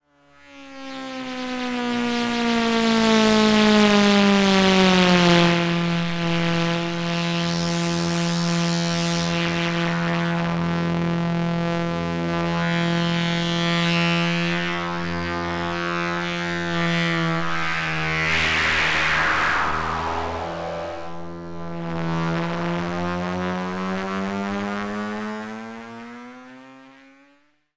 No other effects processing was used other than some normalization, and each example is a single track. All effects and pitch modulation were improvised using the FLUX bows.
AG-8-Bit01.mp3